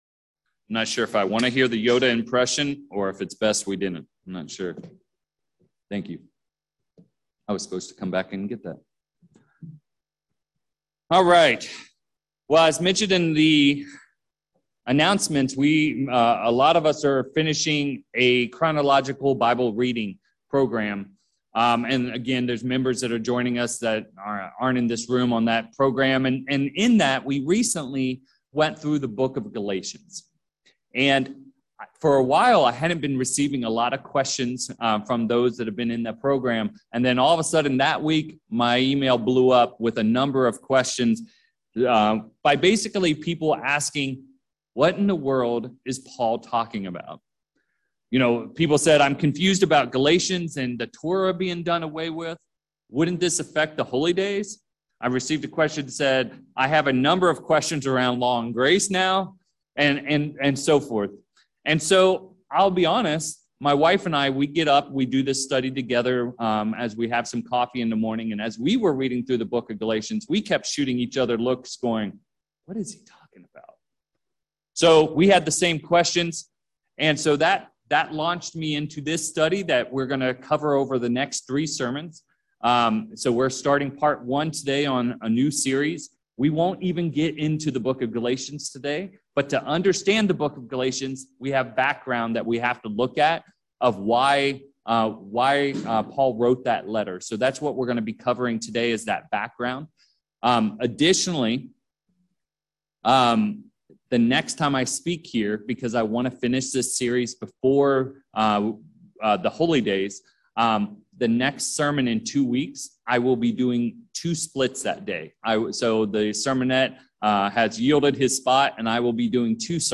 In this three-part sermon, we will prove this to be a complete distortion of Paul’s writing. In this first part, we will dive into the events leading up to Paul writing this letter (background to the book of Galatians), including his background/education and his first sermon given to the Galatia region prior to writing the letter recorded in Acts 13 and 14.